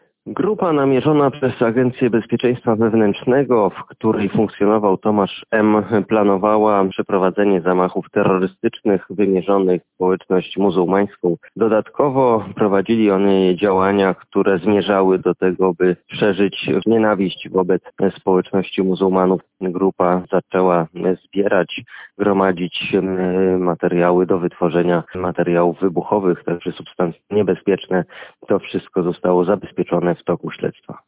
– Mówi Stanisław Żaryn, rzecznik prasowy Ministra Koordynatora Służb Specjalnych.